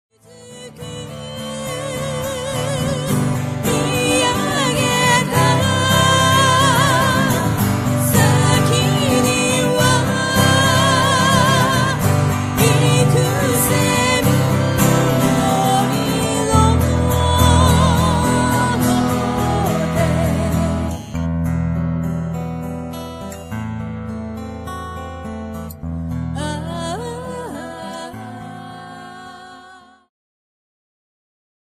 ジャンル アコースティック
ボーカルフューチュア
癒し系